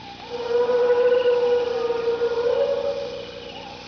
Exclamation.wav